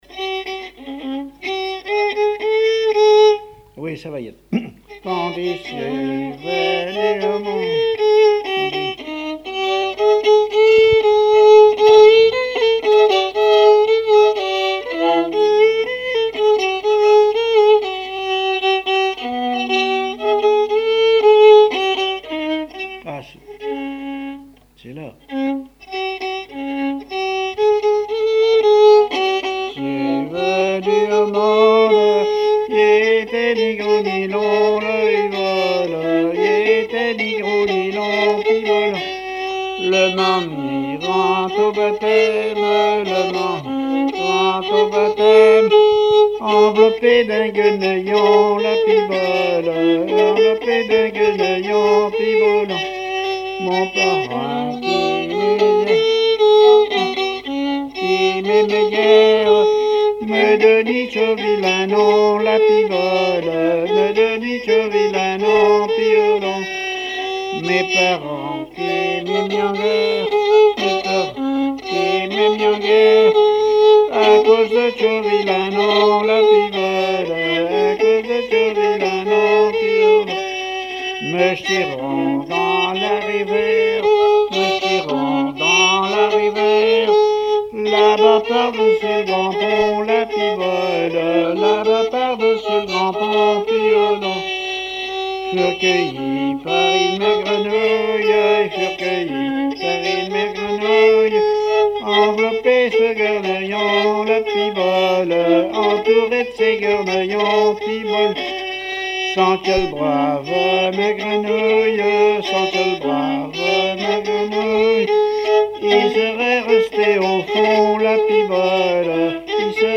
Mémoires et Patrimoines vivants - RaddO est une base de données d'archives iconographiques et sonores.
Genre laisse
répertoire musical au violon
Pièce musicale inédite